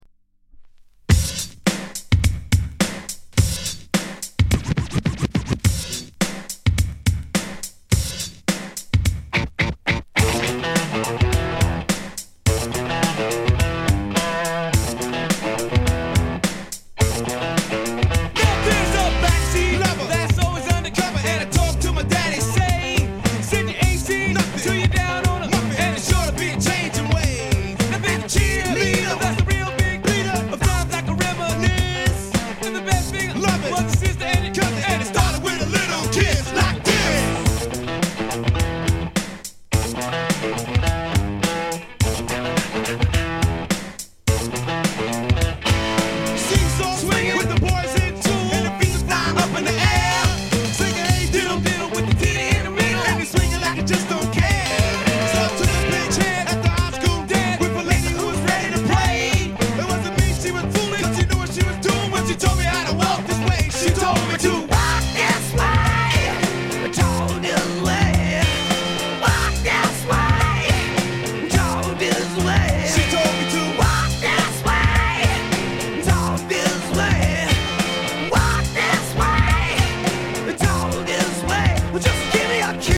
category Rap & Hip-Hop